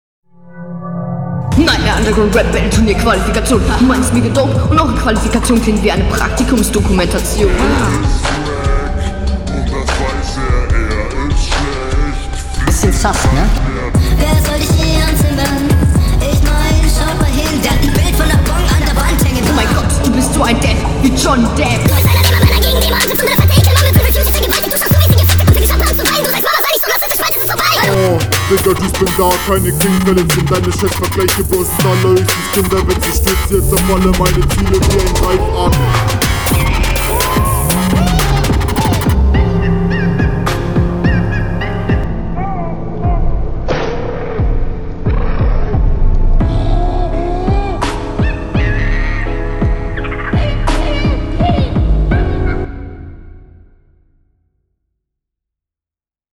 Battle Runden